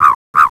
DOVE.WAV